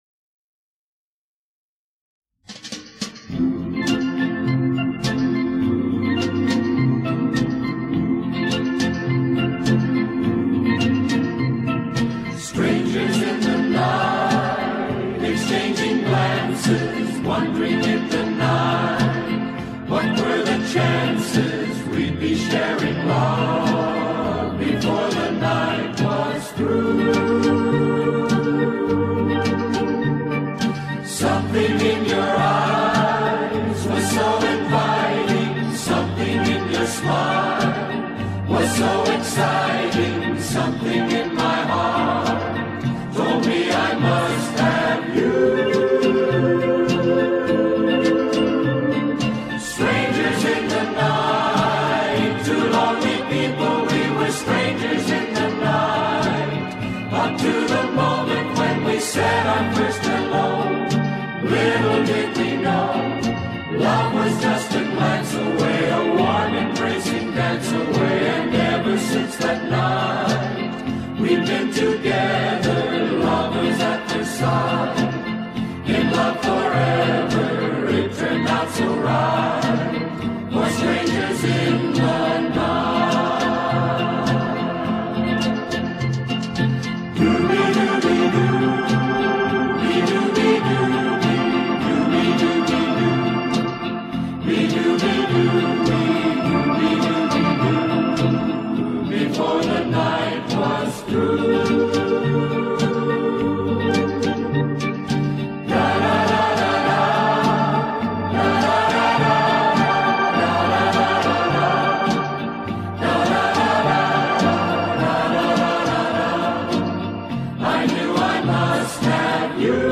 Genre:Easy Listening